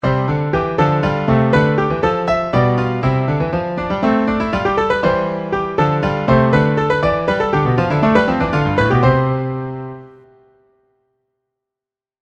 Textura contrapuntística. Ejemplo.
piano
contrapunto